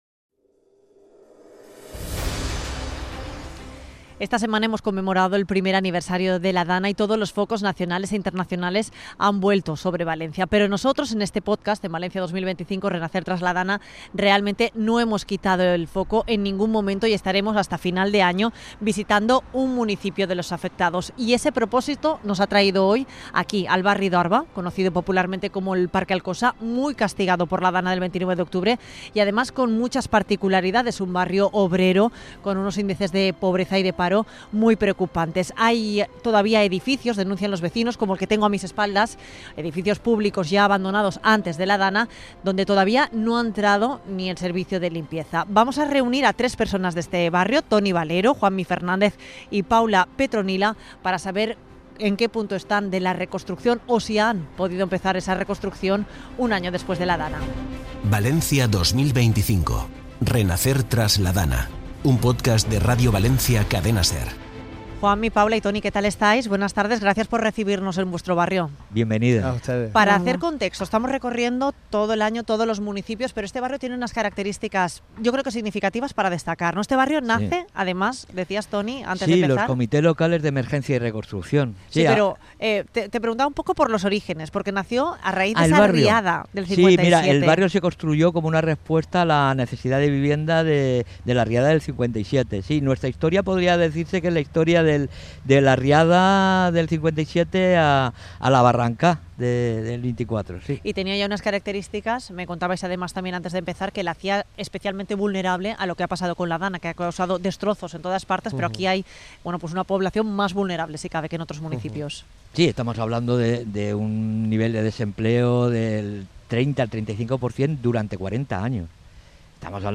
Se cumple un año de la DANA y la semana del 29 de octubre visitamos el barri d'Orba, también conocido popularmente como Parque Alcosa, en Alfafar. Un barrio sufrió gravemente las inundaciones y formado por familias de clase trabajadora, con un ídice de paro del 35% y unos umbrales de pobreza superiores al de otros municipios de l'Horta Sud.